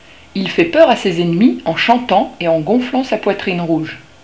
Le rouge gorge
Il fait peur à ses ennemis en chantant et en gonflant sa poitrine rouge.